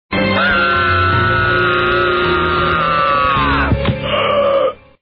couragescream02.wav